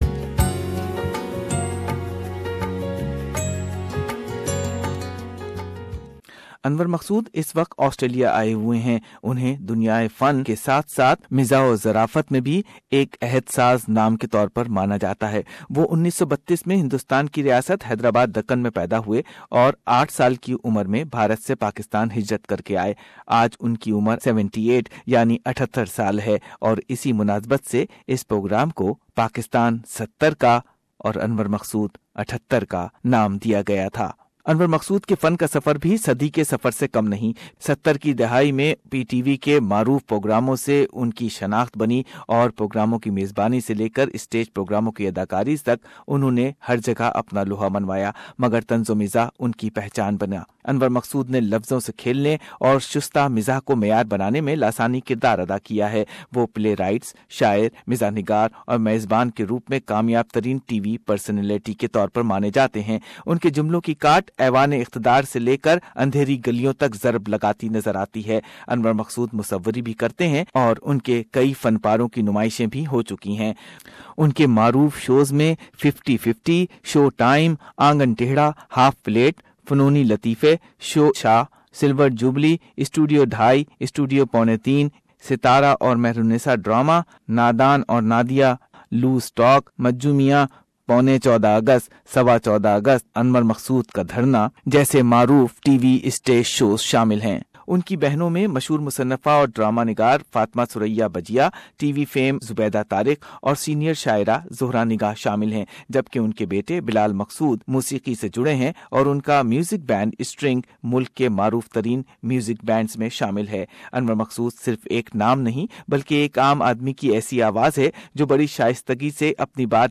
Anwar Maqsood is visiting Australia and we filtered out some selective audio grabs from his performance in Sydney (Courtesy Koscha e Saqafat Australia)